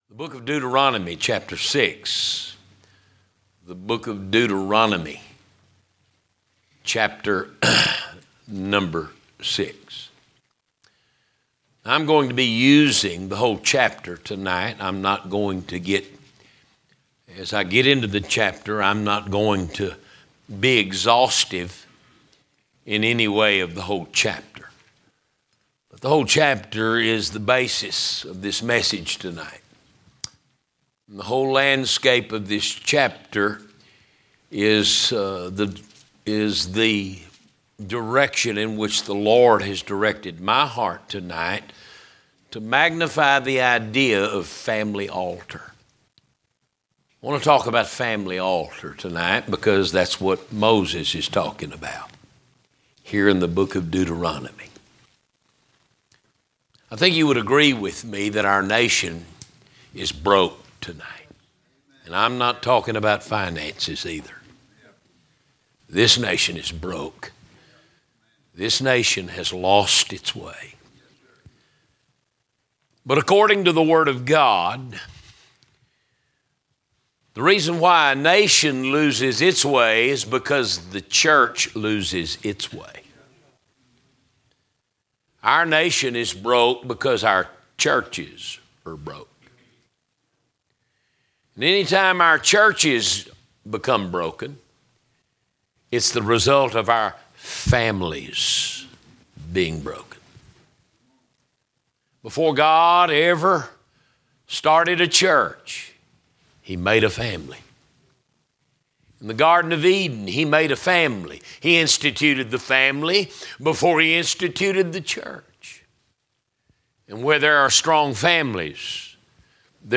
Sermons - Emmanuel Baptist Church
From Series: "General Preaching"